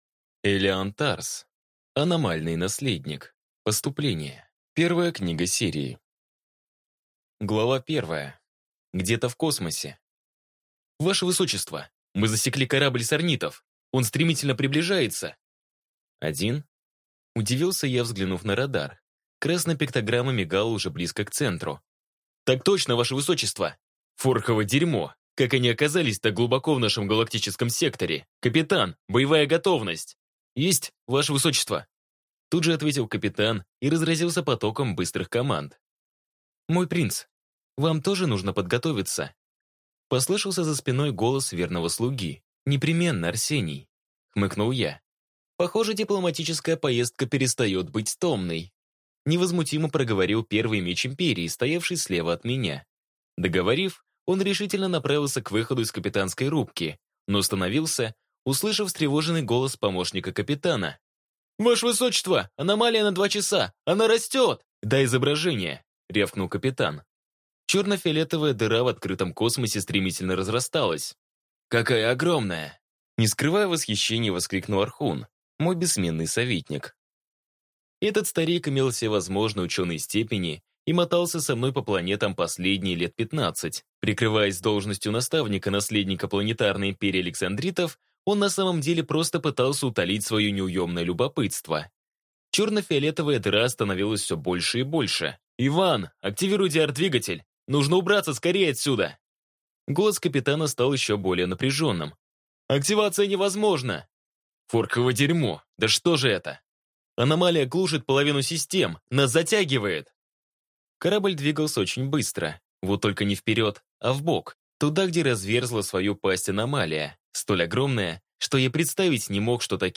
Аудиокнига Аномальный наследник. Поступление | Библиотека аудиокниг